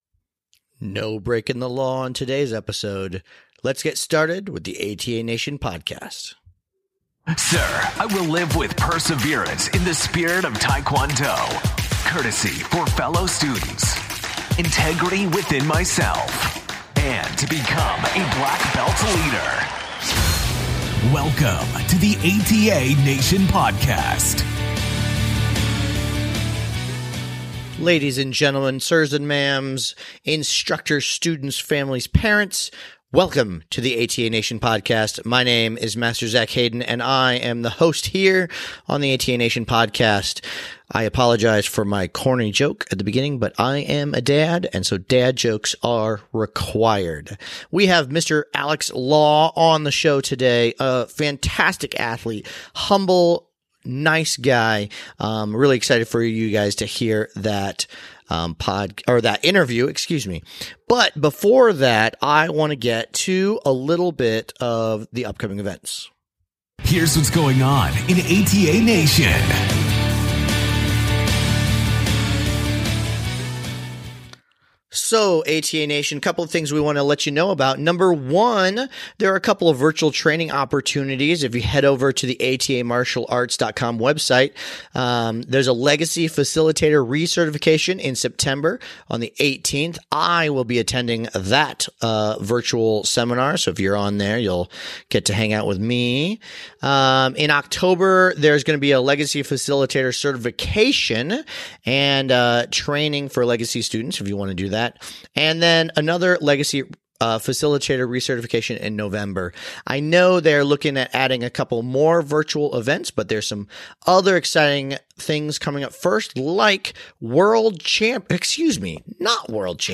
Ep. 23 - An Interview with World Champ